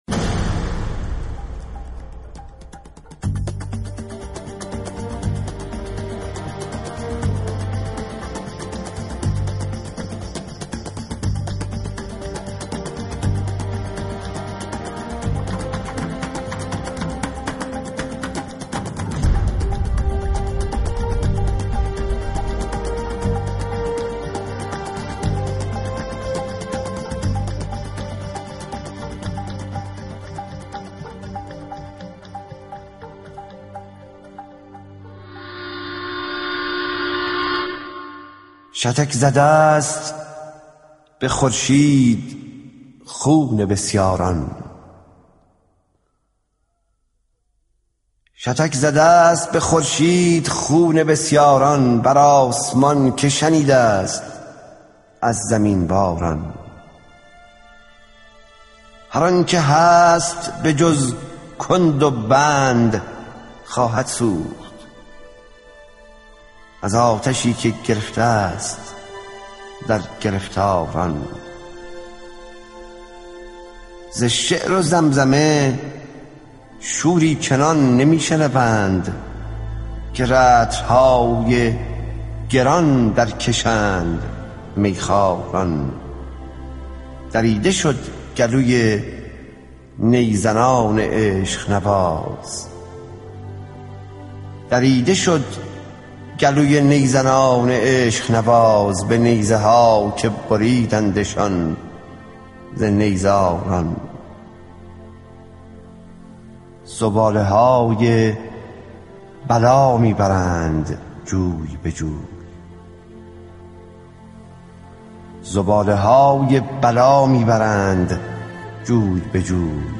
دانلود دکلمه شتک زده است با صدای حسین منزوی
گوینده :   [حسین منزوی]